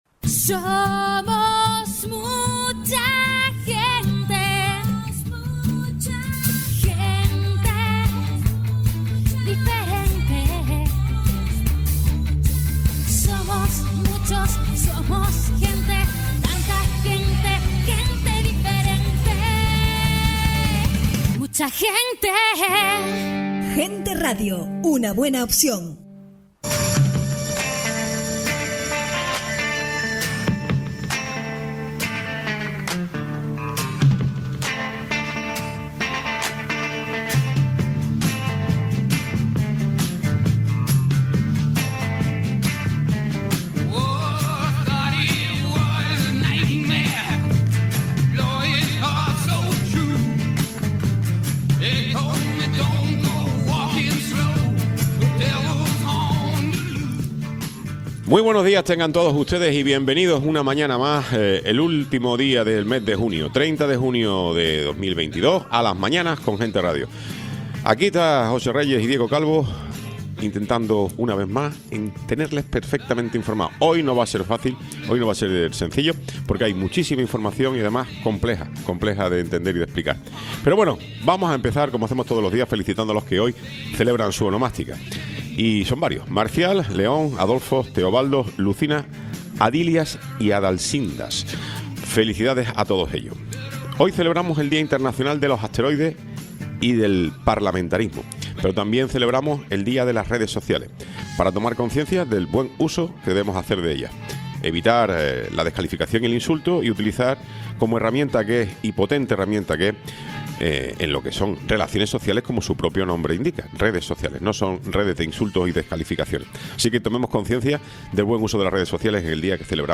Tiempo de entrevista con Manuel Fernández Vega, Consejero PP en el Cabildo de Tenerife
Tertulia